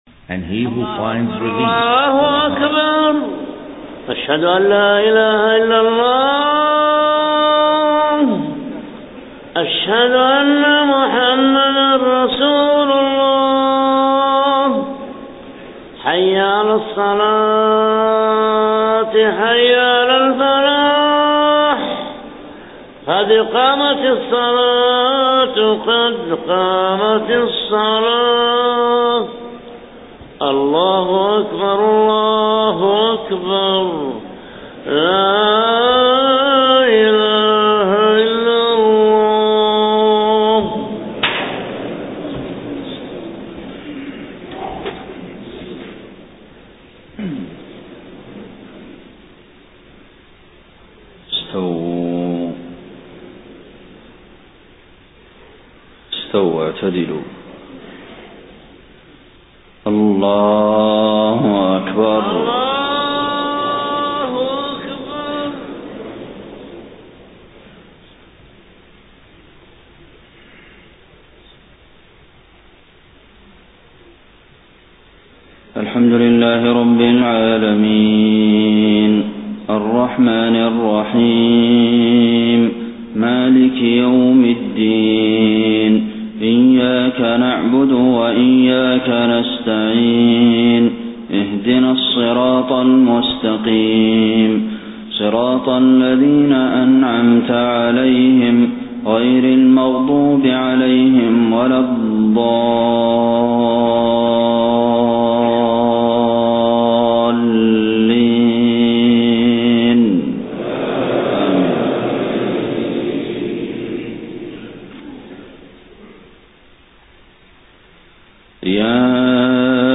صلاة الفجر 15 ربيع الأول 1431هـ من سورة الأنفال 20-34 > 1431 🕌 > الفروض - تلاوات الحرمين